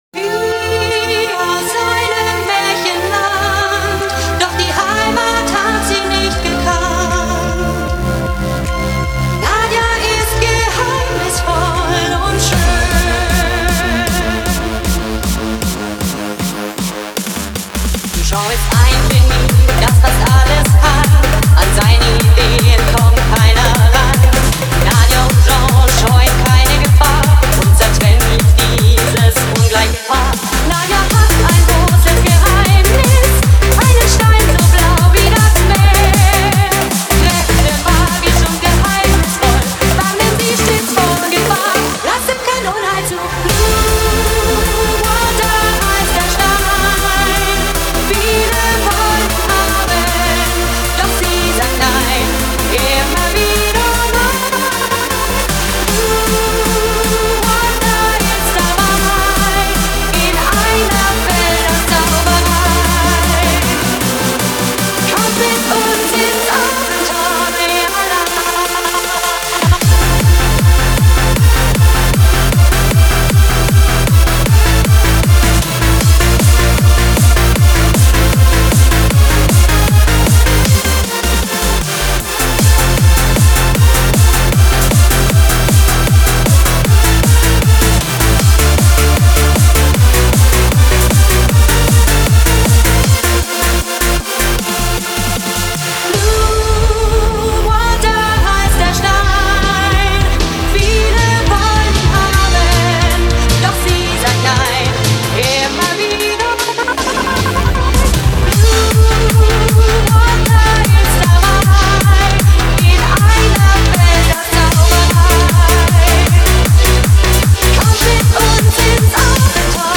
Bootlegs